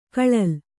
♪ kaḷal